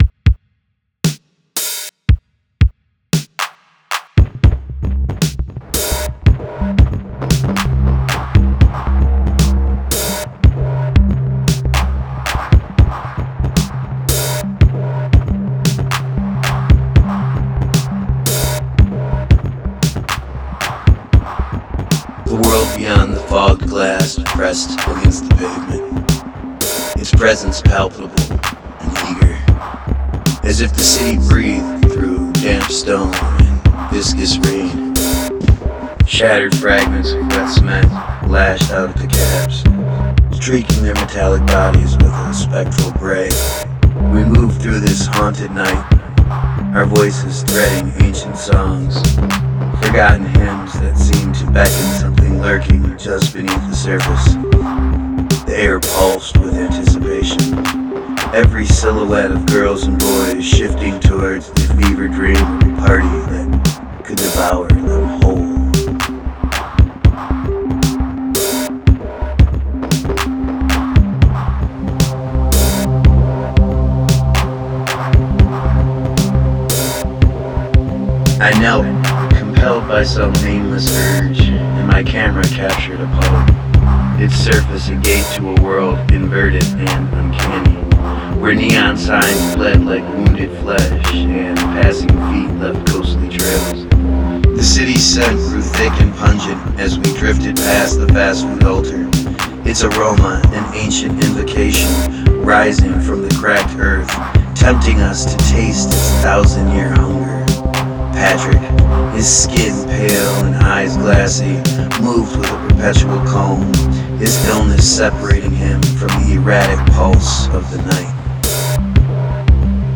free electronica live sessions